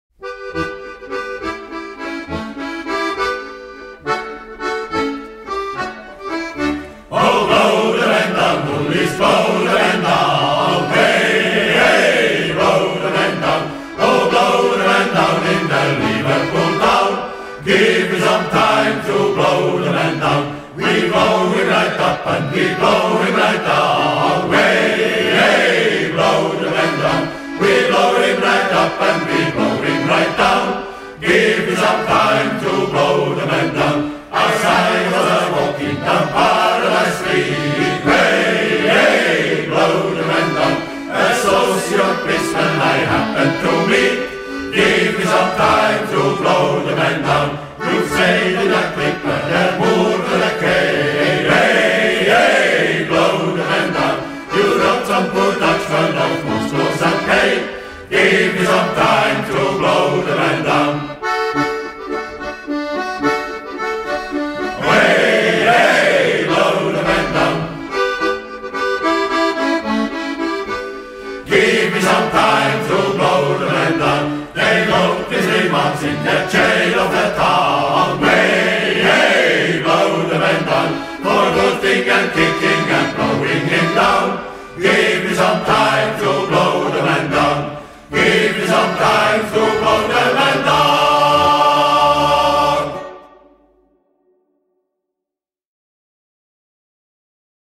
Die Shantycrew Tribschenhorn Luzern ist ein traditioneller Shanty Chor aus der Zentralschweiz, der seit vielen Jahren mit maritimen Liedern und Seemannsshantys für unvergessliche Stimmung sorgt.
Shantys sind traditionelle Arbeits- und Seemannslieder, die früher an Bord von Segelschiffen gesungen wurden.
Der Klang ist kräftig, warm und mitreissend – das Publikum wippt mit, singt mit und taucht für einen Moment in die Welt der Seefahrer ein.